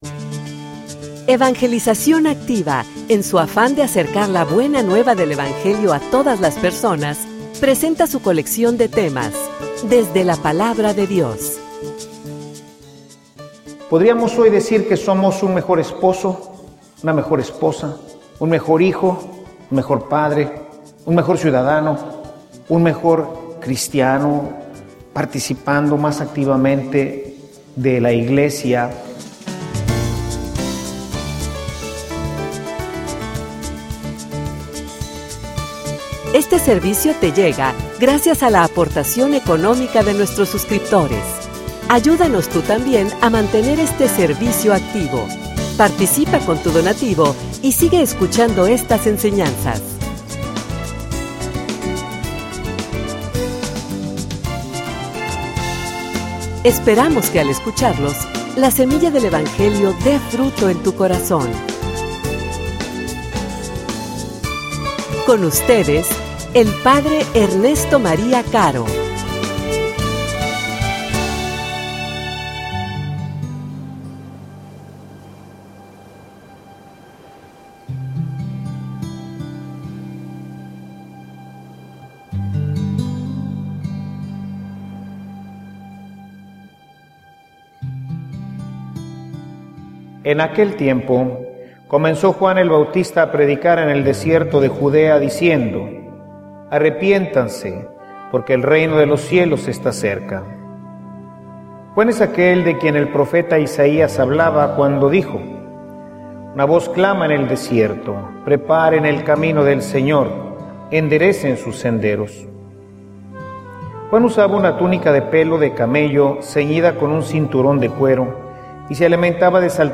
homilia_Se_camino_para_los_demas.mp3